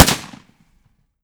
Gunshots / 30-30 Lever Action Rifle - Gunshot B 002.wav